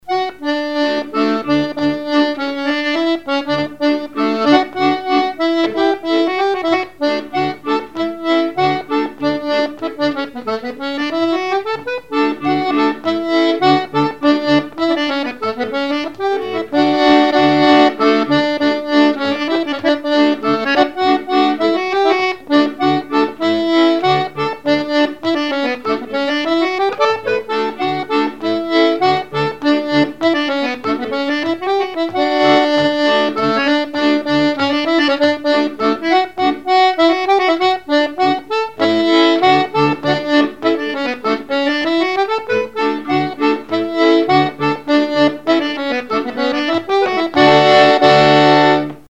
Chants brefs - A danser
scottich sept pas
instrumentaux à l'accordéon diatonique
Pièce musicale inédite